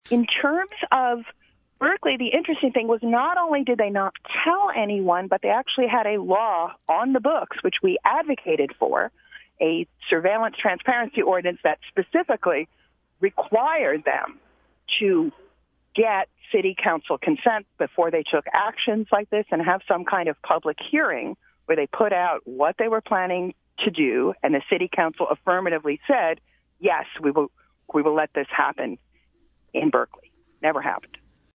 In-Depth Interview: Activist Challenge Video Surveillance in Uber-Liberal Berkeley